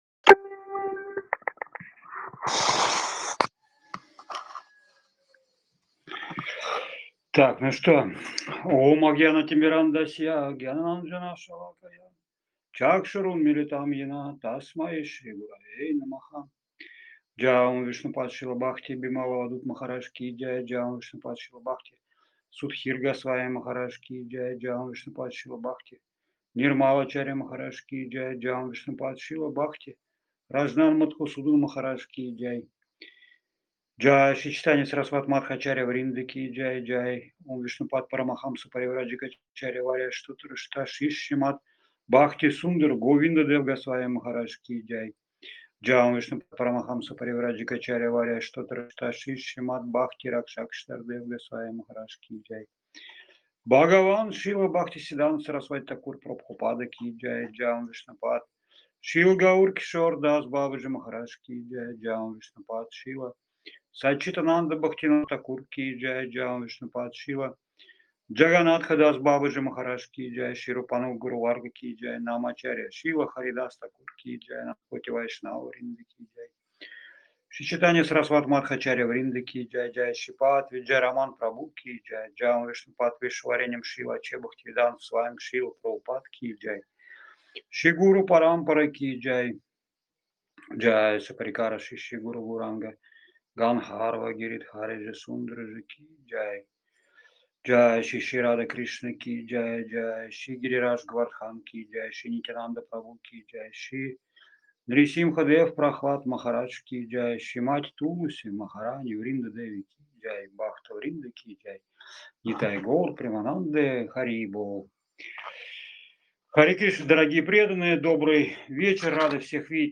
Лекции полностью